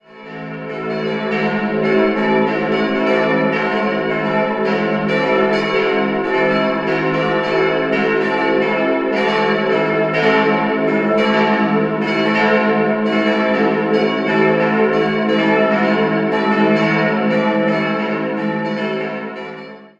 Nach Kriegszerstörungen konnte das Gotteshaus bis 1956 wiederhergestellt werden. 4-stimmiges Geläute: e'-fis'-a'-cis'' Die zweitgrößte Glocke wurde 1706 von Herold gegossen, die anderen drei im Jahr 1958 von Rincker.